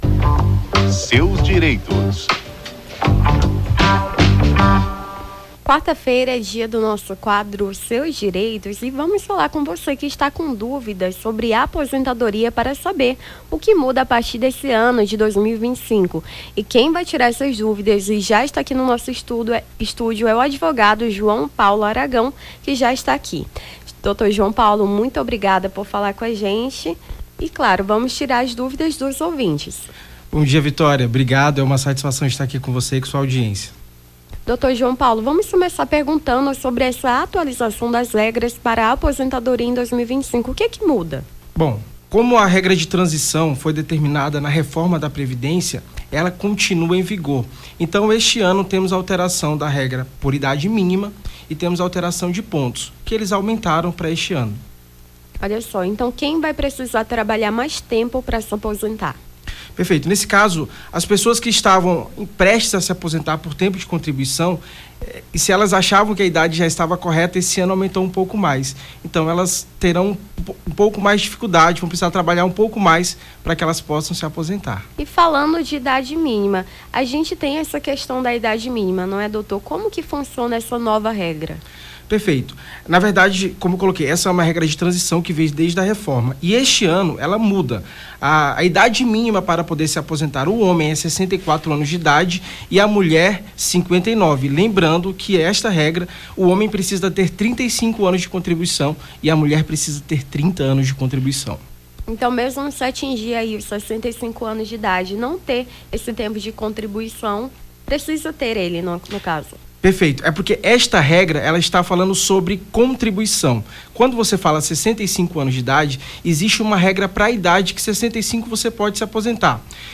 No Jornal da Manhã dessa quarta-feira (08)